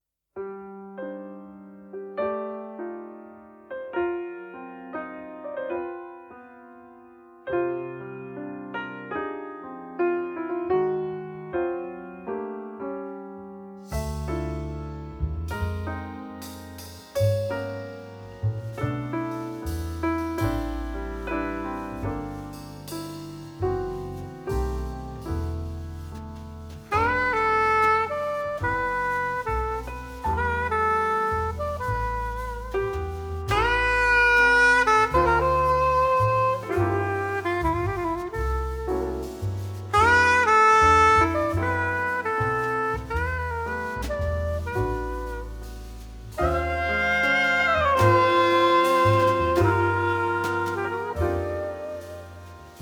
The Best In British Jazz
Recorded at Fish Factory Studio, London 2016